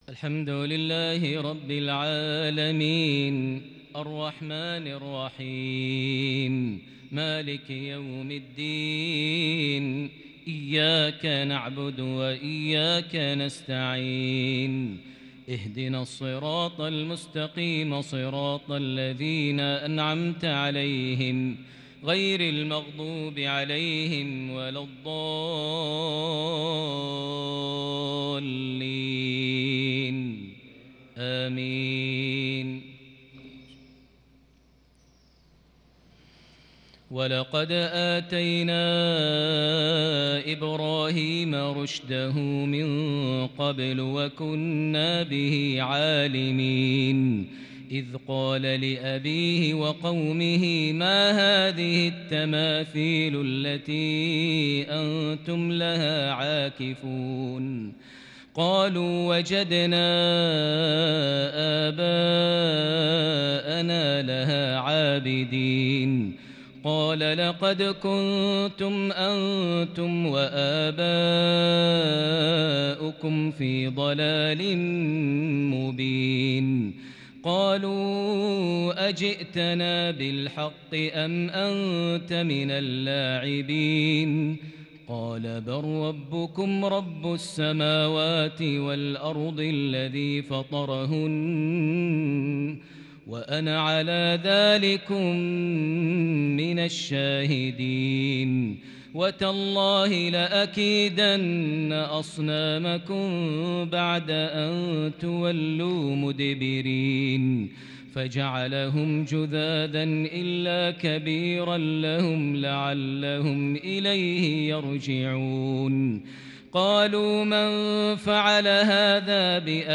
قصة إبراهيم بتلاوة متألقة بتفرد وتحبير الكرد من سورة الأنبياء (51-72) | 6 شعبان 1442هـ > 1442 هـ > الفروض - تلاوات ماهر المعيقلي